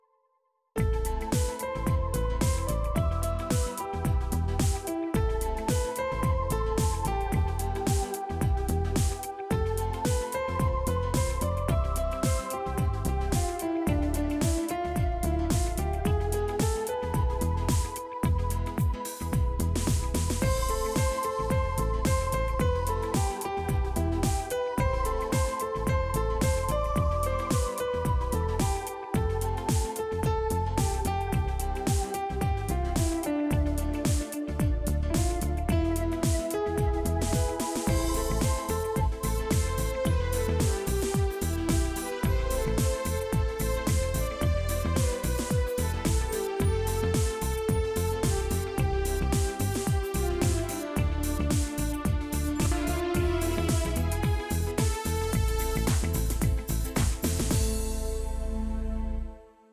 Может я что-то неточно наиграл ))) не слышал ее с 87-го года